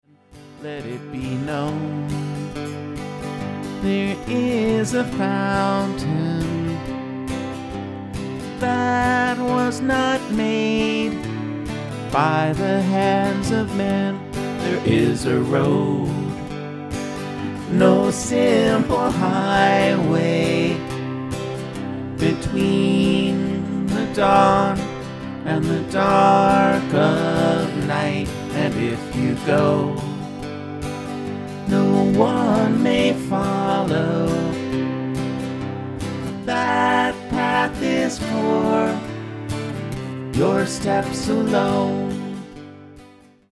There are no overdubs here; this is all done in real-time.